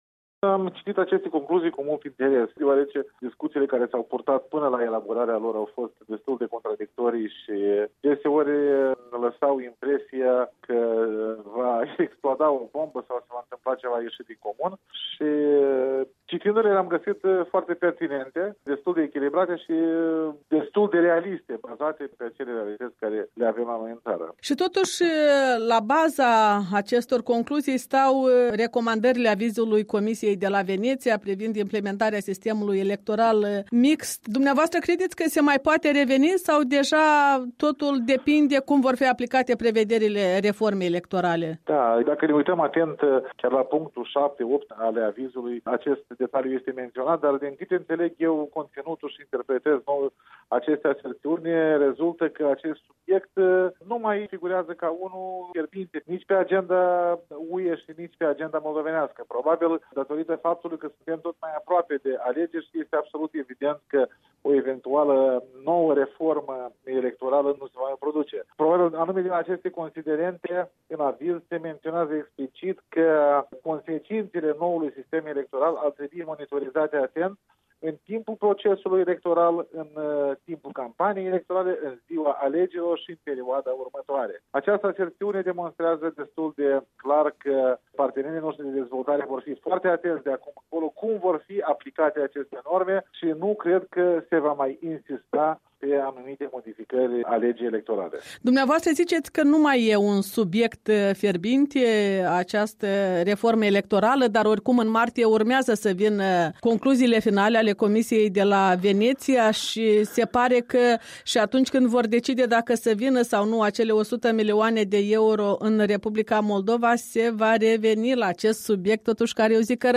Dialog cu ministrul moldovean al justiției despre concluziile Consiliul pentru afaceri externe al UE privind progresele și restanțele Chiținăului în aplicarea reformelor.